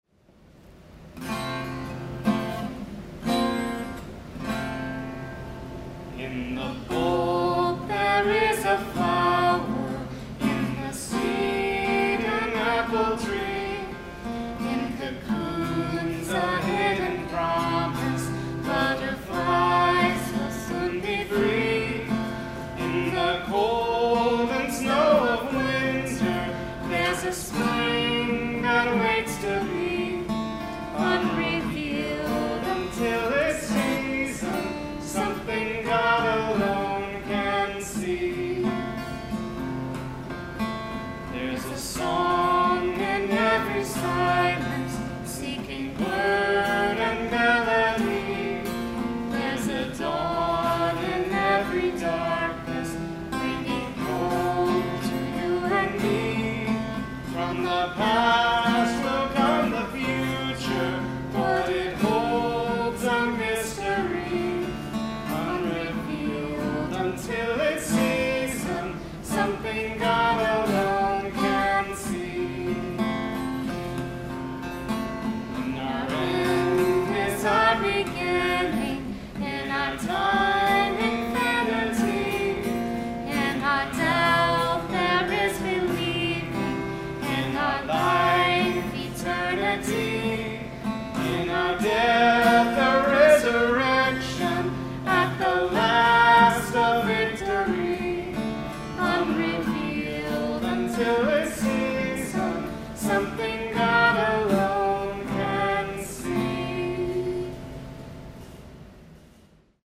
Recorded on March 29, 2020 at Algiers United Methodist Church in New Orleans, Louisiana.